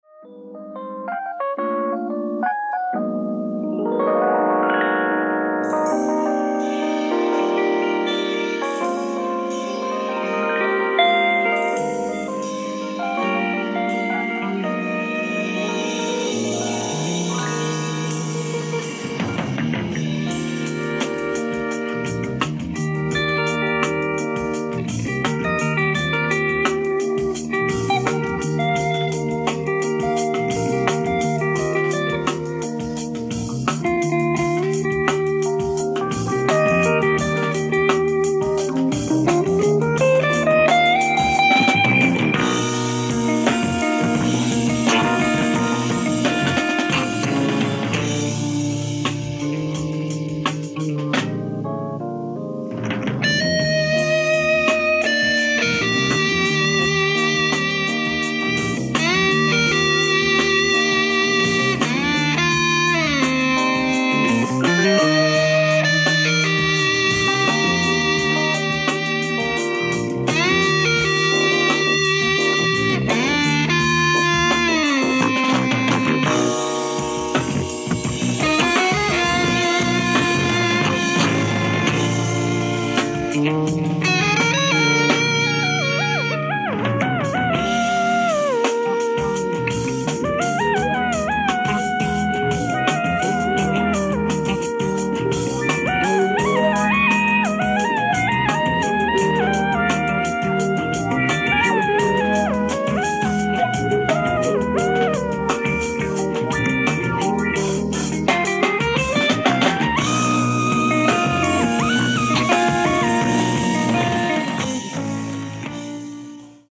ライブ・アット・エレクトリック・レディ・スタジオ、ニューヨーク 06/1975
※試聴用に実際より音質を落としています。